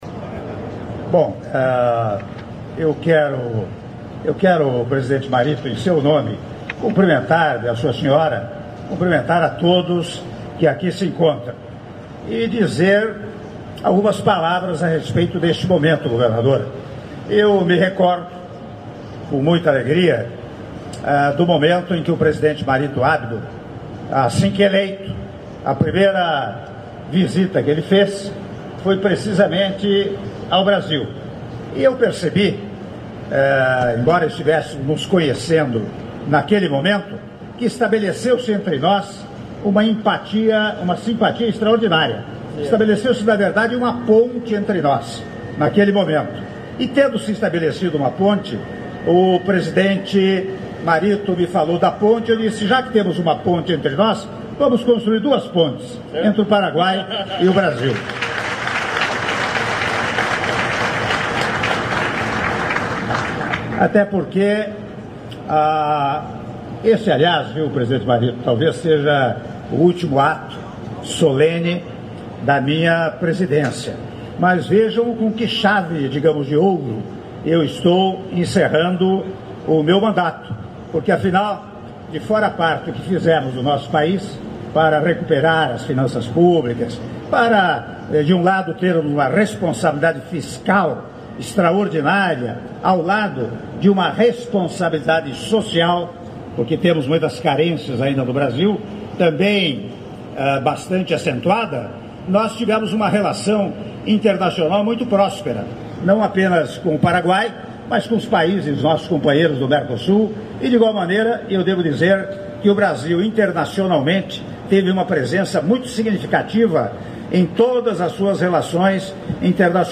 Áudio do discurso do Presidente da República, Michel Temer, durante cerimônia de assinatura da Declaração Presidencial Conjunta Brasil-Paraguai sobre Integração Física - Foz do Iguaçu/PR (05min30s)